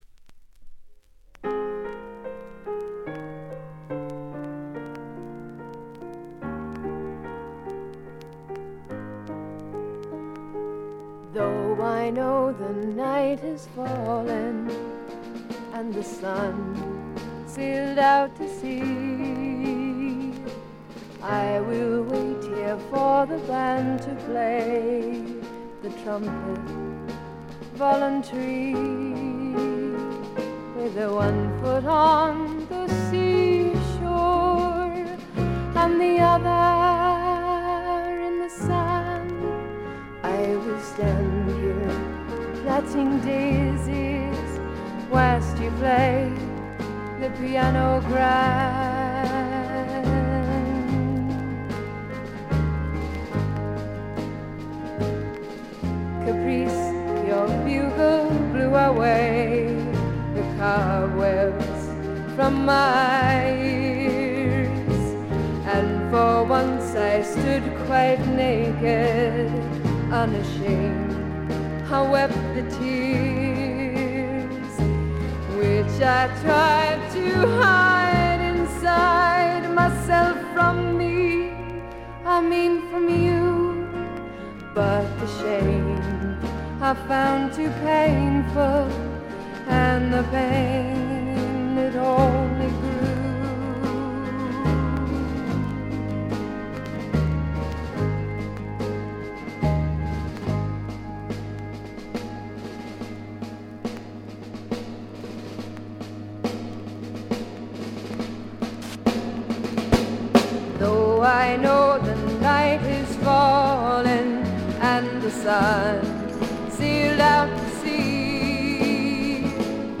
細かなチリプチ程度でまずまず良好に鑑賞できると思います。
試聴曲は現品からの取り込み音源です。
vocals